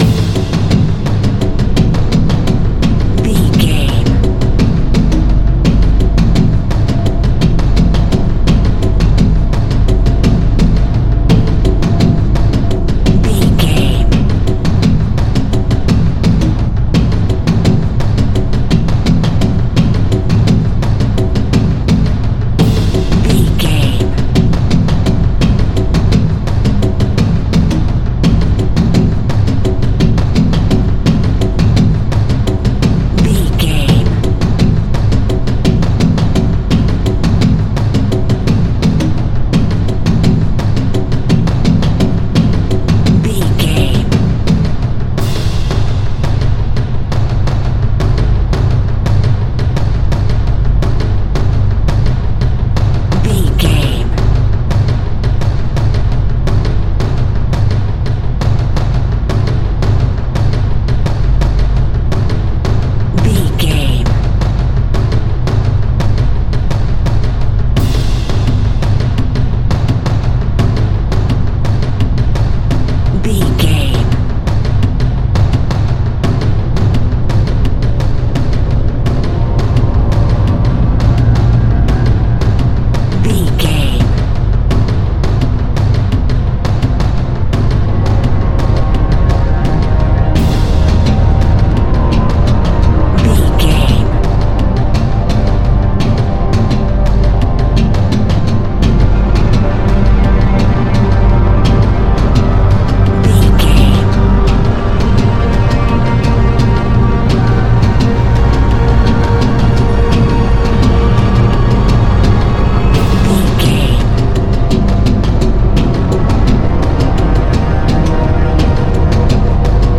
In-crescendo
Atonal
Fast
scary
tension
ominous
dark
eerie
drums
percussion
strings
synthesiser
Synth Pads
atmospheres